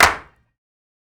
Clap (Slow).wav